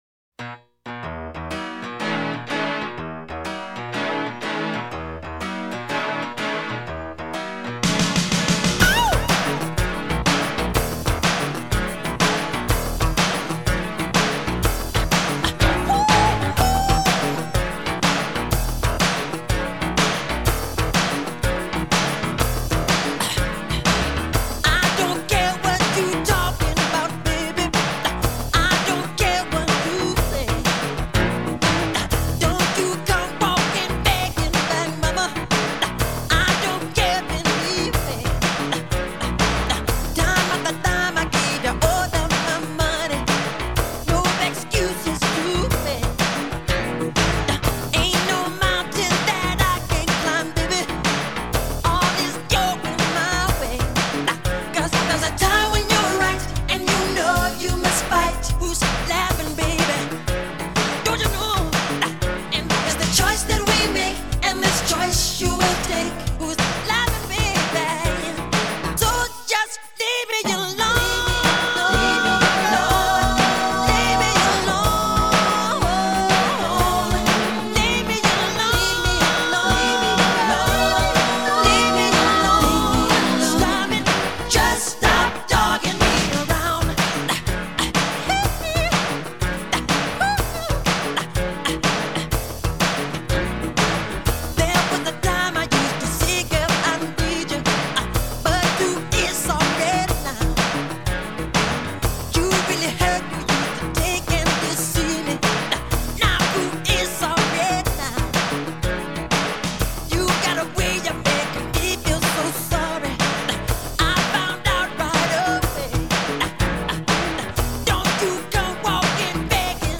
Жанр: Soul